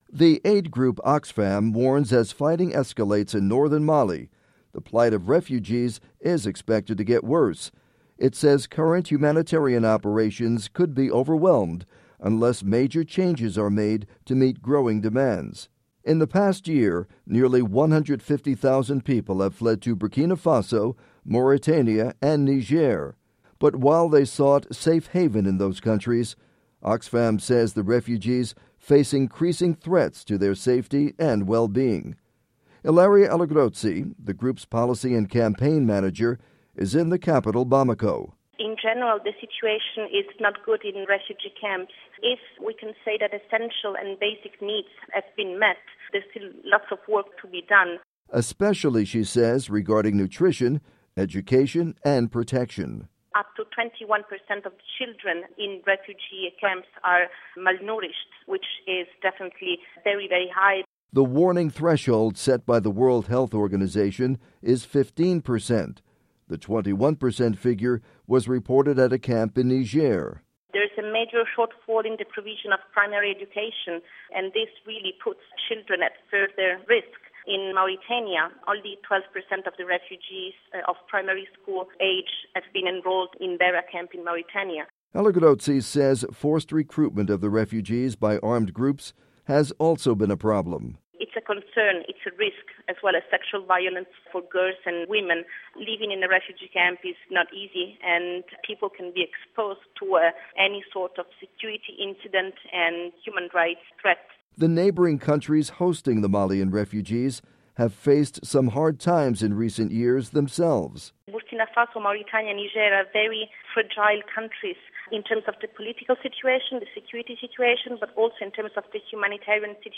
report on Mali refugees